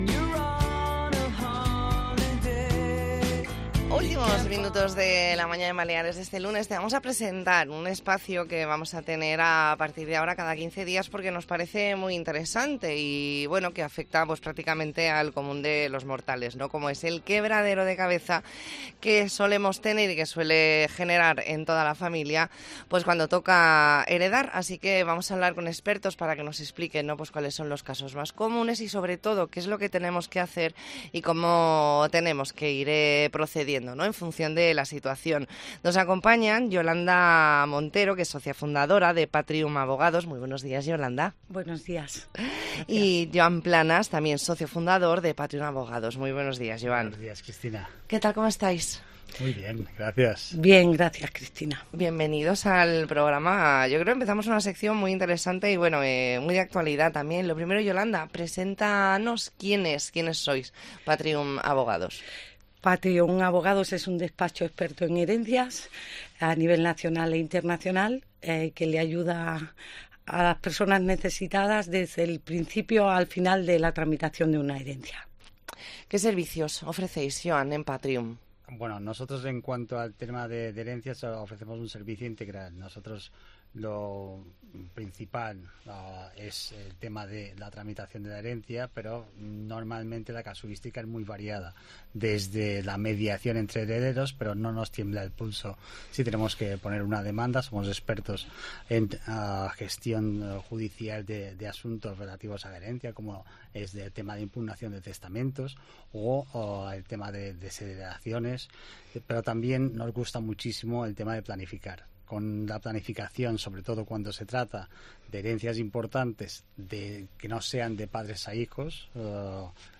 Entrevista en La Mañana en COPE Más Mallorca, lunes 4 de diciembre de 2023.